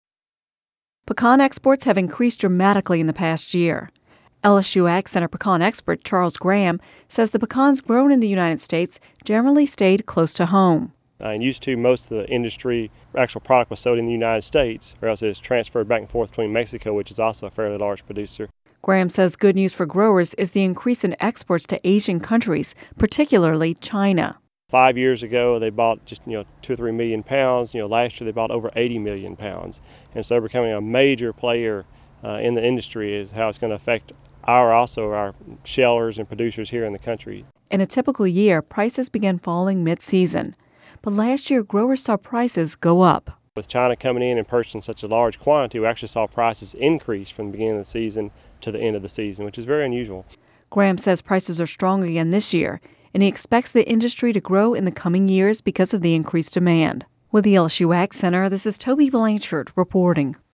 (Radio News 11/08/10) Pecan exports have increased dramatically in the past year.